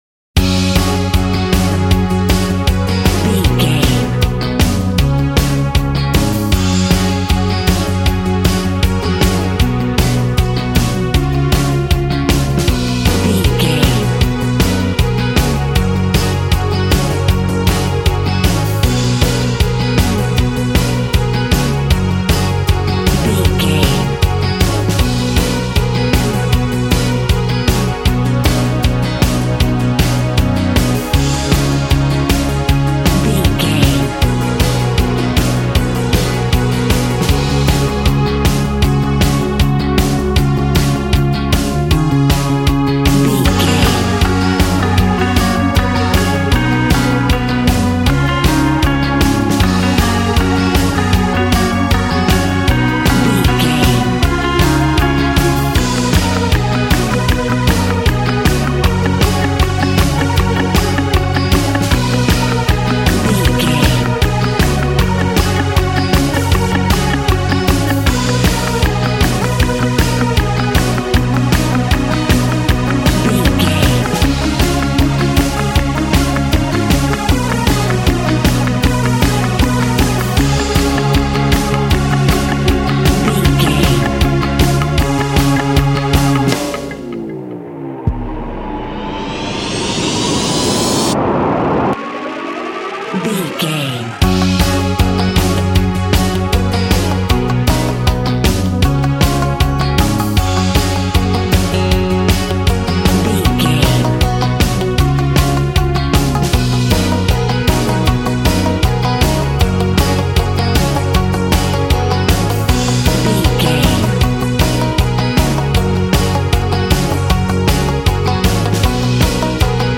Ionian/Major
G♭
groovy
powerful
organ
drums
bass guitar
electric guitar
piano